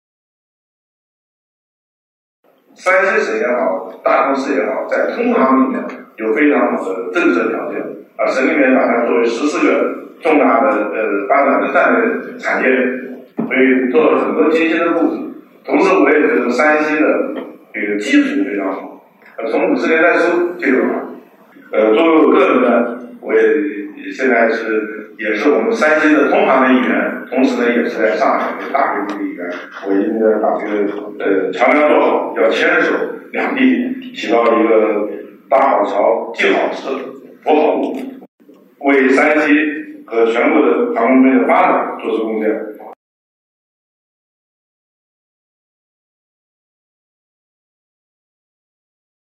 2020年11月5日在第三届中国国际进口博览会期间，大同通航产业集群专题招商推介会在上海隆重举行。
山西通用航空职业技术学院技术委员会主任、中国工程院院士、飞机设计专家吴光辉讲道：